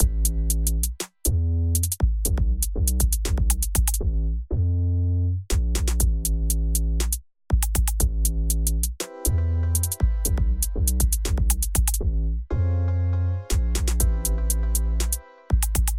*The hip-hop beat drops. Or whatever you call it*